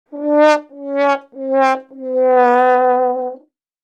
Fail Sound Effect Free Download
Fail